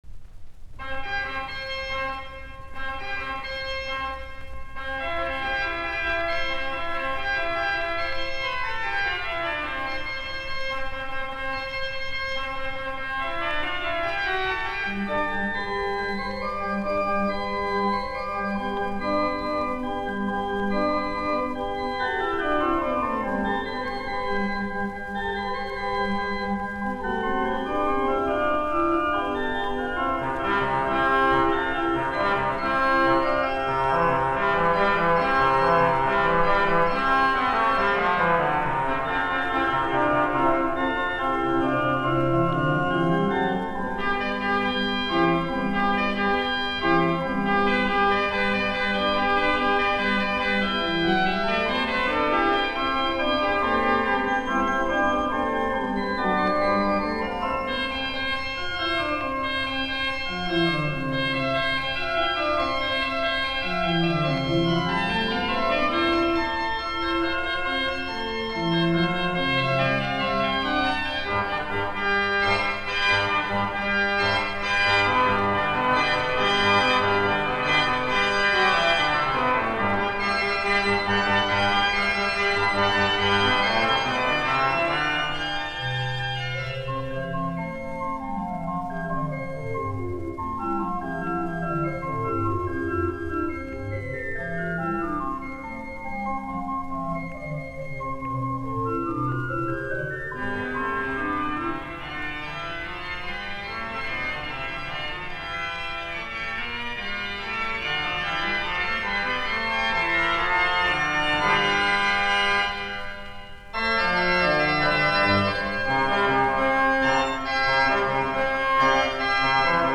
Fuugat, urut, BWVAnhII90, C-duuri